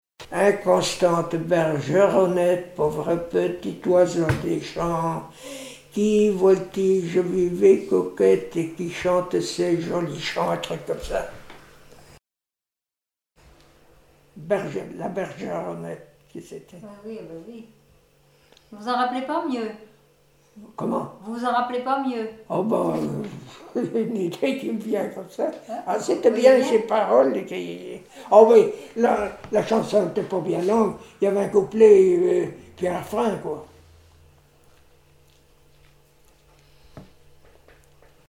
Genre strophique
Musique et témoignages
Pièce musicale inédite